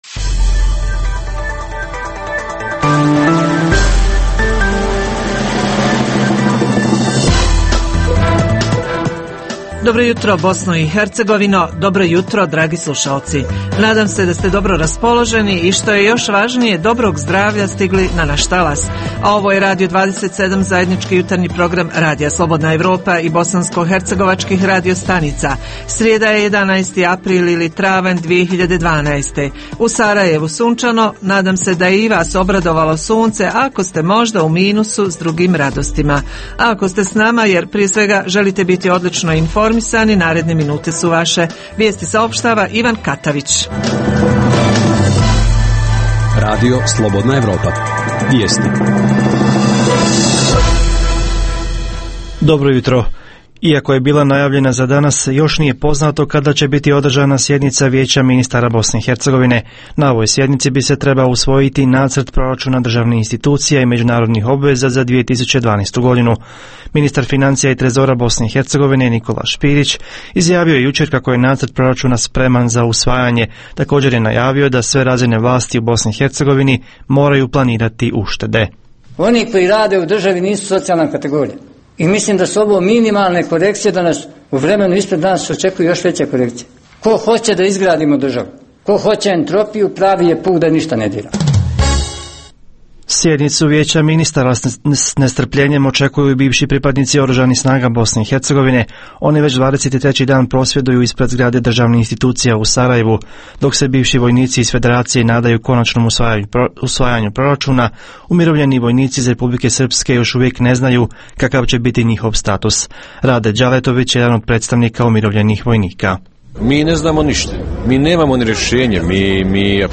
Jutarnji program za BiH koji se emituje uživo. Tema jutra: Psihološka savjetovališta za mlade i one koji više nisu mladi, za specijalne kategorije PTSP i slično Reporteri iz cijele BiH javljaju o najaktuelnijim događajima u njihovim sredinama.